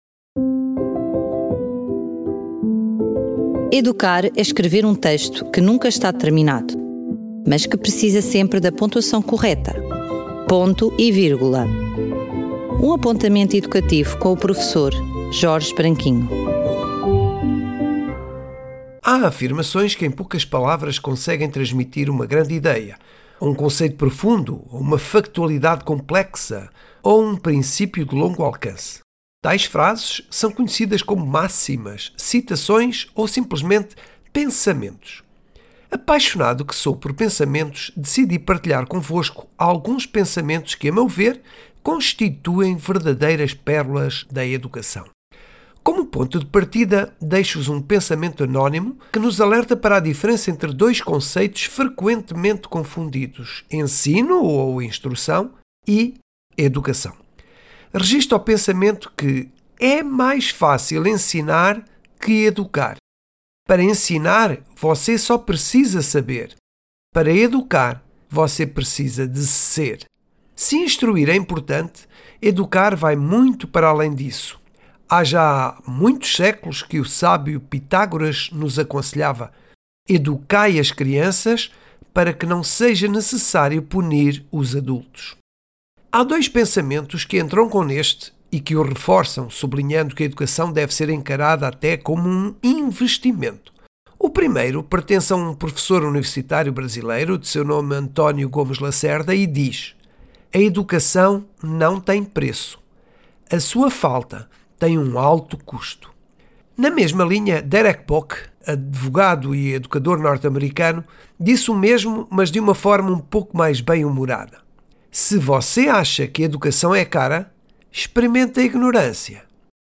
Rubrica semanal, apresentada na forma de um apontamento educativo e a partir da longa experiência de um professor, que aborda os desafios e os diferentes ângulos do universo da Educação.